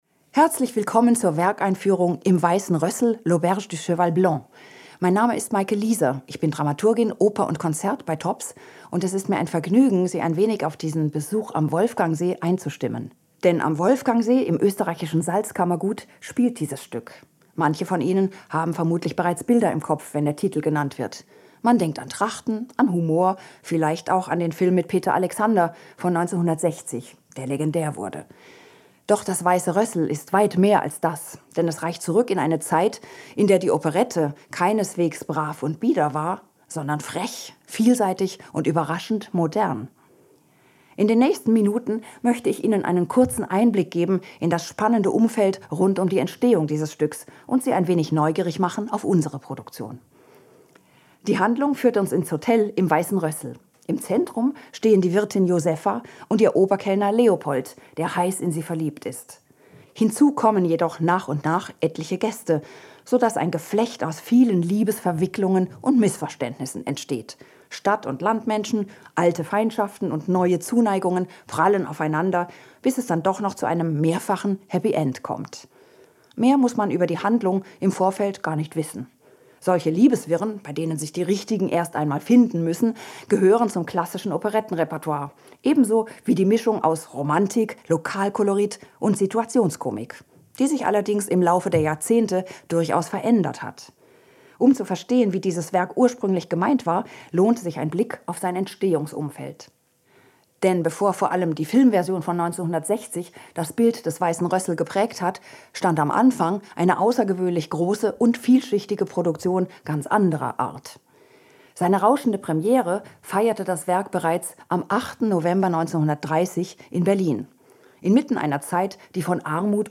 Pause) ALTERSEMPFEHLUNG 14+ AUDIO WERKEINFÜHRUNG Jetzt anhören SRF 2 KULTUR Podcast (ab 05:19) Jetzt anhören DIGITALES PROGRAMMHEFT Jetzt lesen